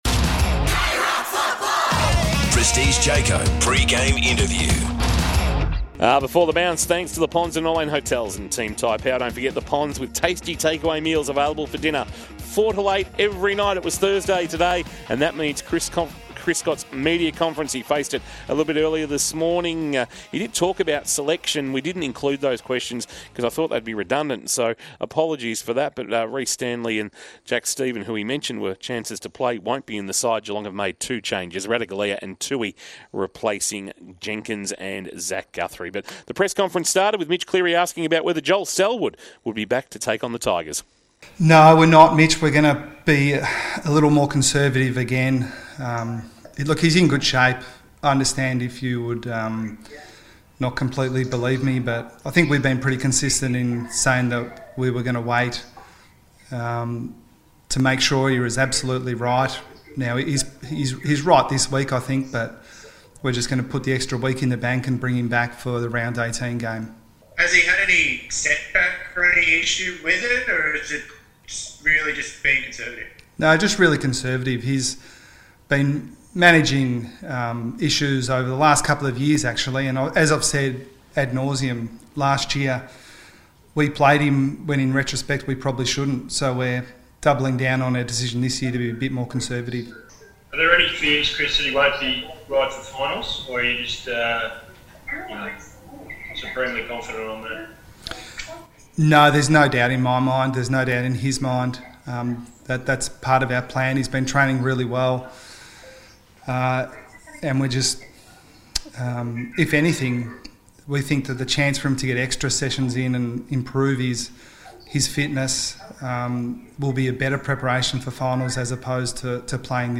POST-MATCH: CHRIS SCOTT - Geelong Coach